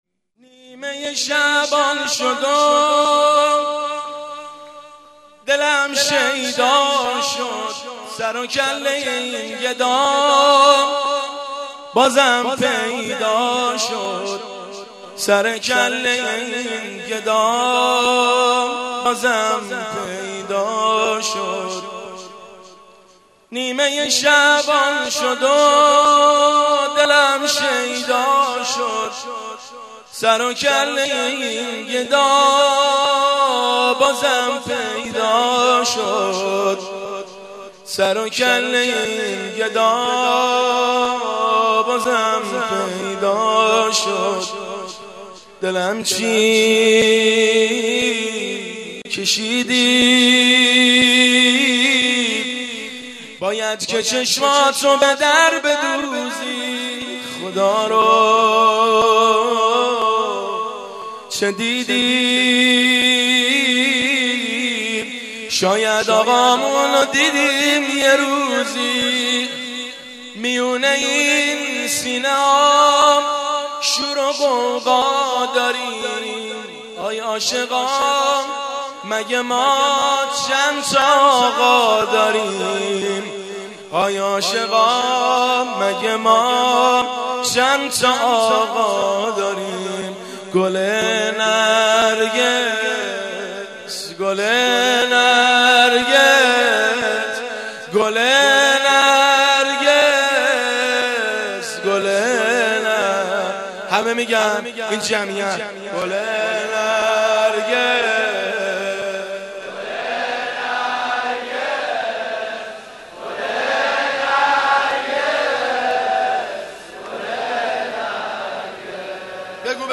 مناسبت : ولادت حضرت مهدی عج‌الله تعالی‌فرج‌الشریف
قالب : سرود